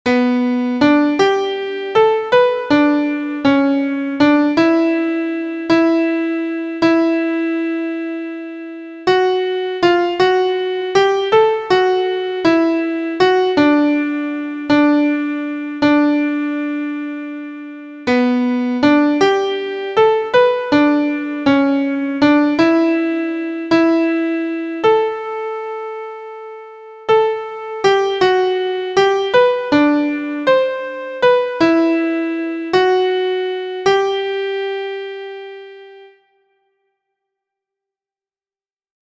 • Key: G Major
• Time: 3/4
• Form: chorus: ABaC*